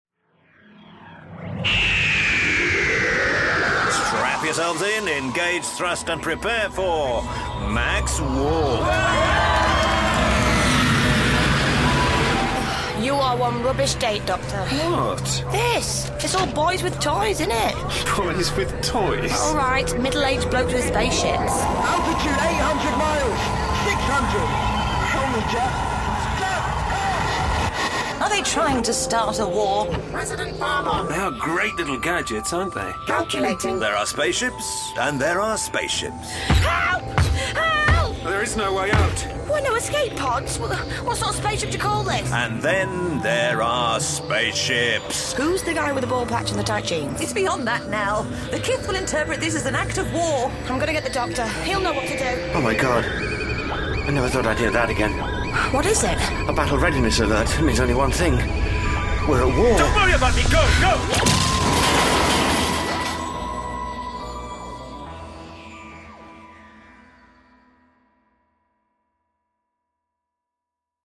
Starring Paul McGann Sheridan Smith